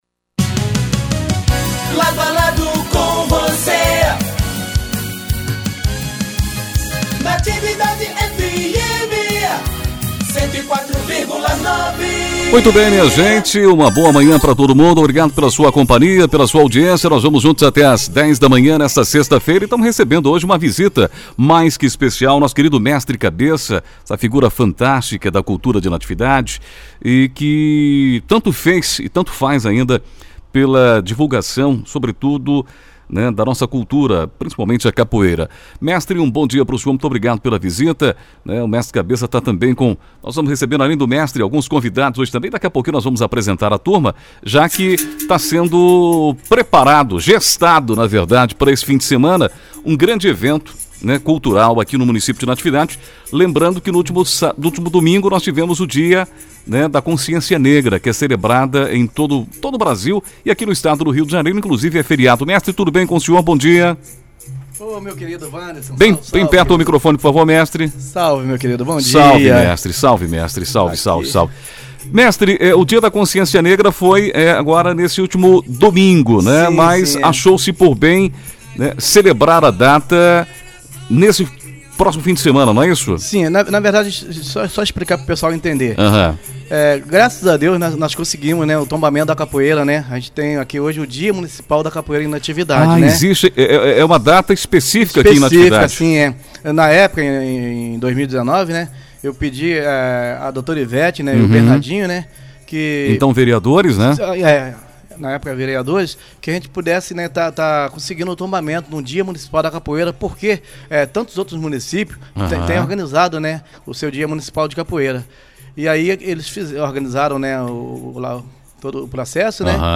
25 novembro, 2022 DESTAQUE, ENTREVISTAS